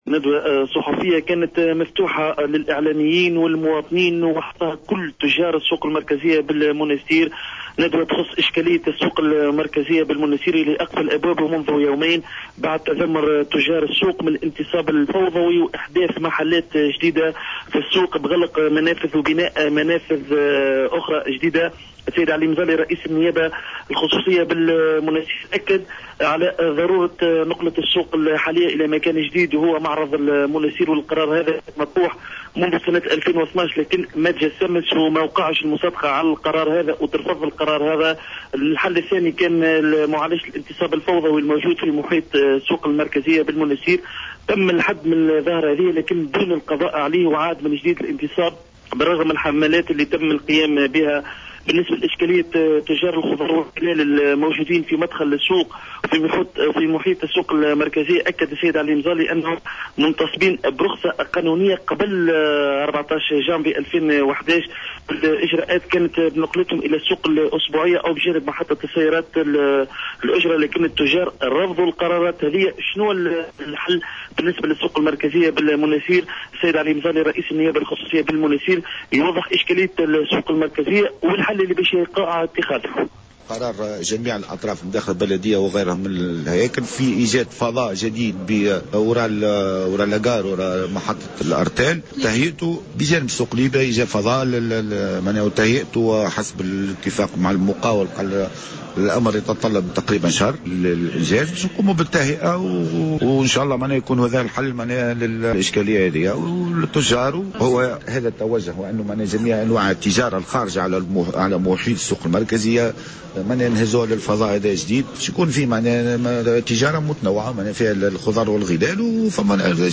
En réaction à la décision des commerçants du marché central de Monastir de poursuivre la fermeture du marché pour la deuxième journée consécutive, le président de la délégation spéciale de la municipalité de Monastir, Ali Mzali a tenu mercredi une conférence de presse pour donner des explications à ce sujet.